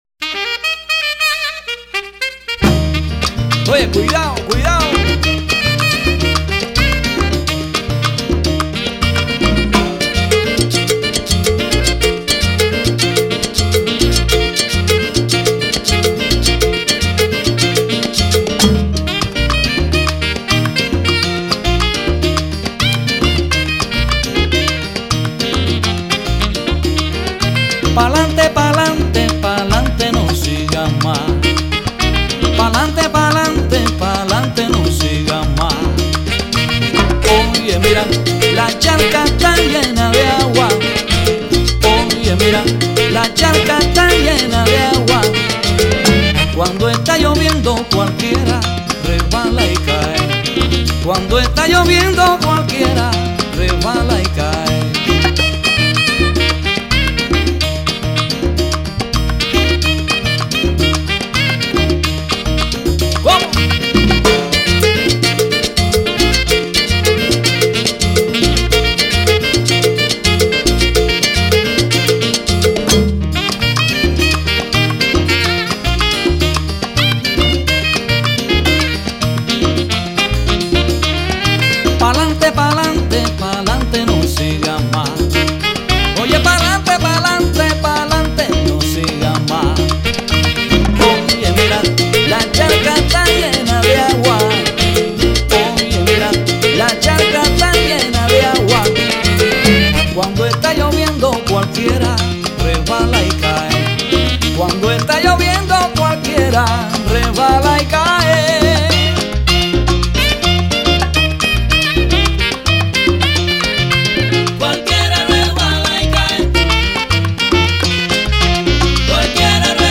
10 excellent and talented American and Cuban musicians
This Miami Latin Band repertoire includes a variety of musical styles such as but not limited to: Salsa, Cumbia, Merengue,Timba, Dance, Top 40, Smooth Latin /American Jazz, Rock and Original Compositions.
In addition, this Miami Latin Band specializes in traditional Cuban music (in the style of Buena Vista Social Club); and has also experience in accompanying all styles of shows (Broadway, Vegas, and Latin Shows).